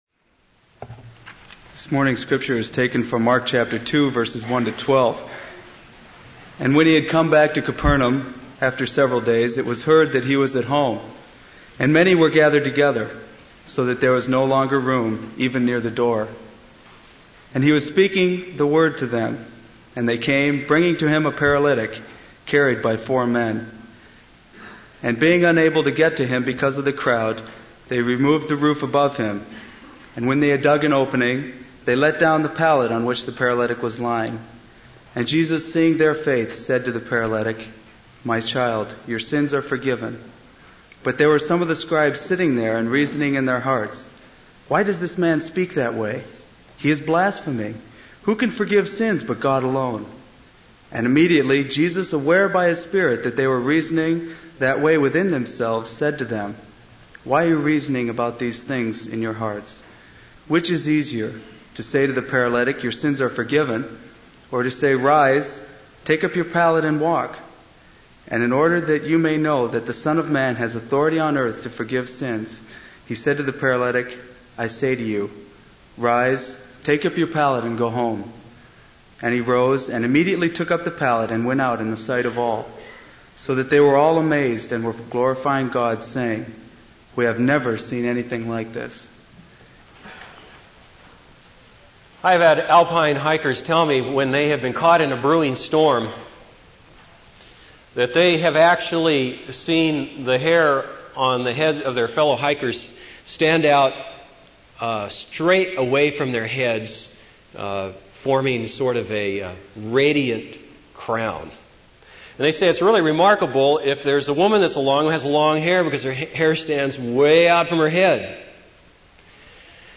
This is a sermon on Mark 2:1-12.